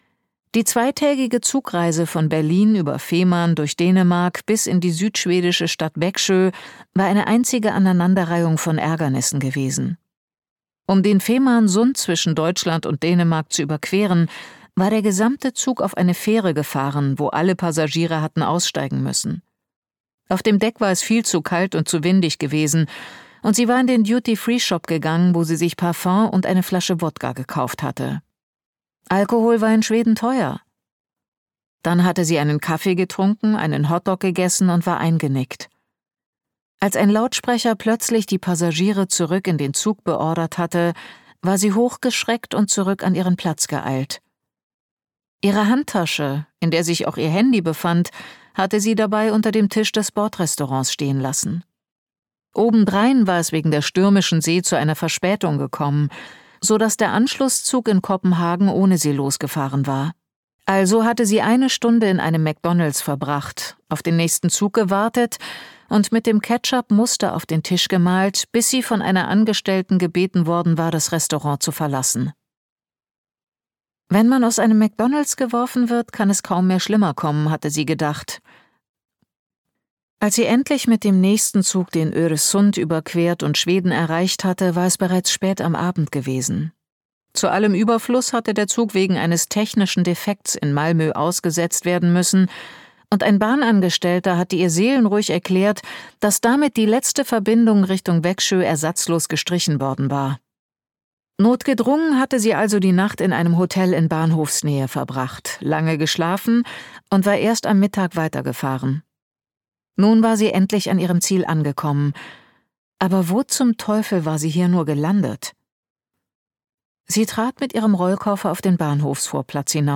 Später Frost - Roman Voosen, Kerstin Signe Danielsson | argon hörbuch
Gekürzt Autorisierte, d.h. von Autor:innen und / oder Verlagen freigegebene, bearbeitete Fassung.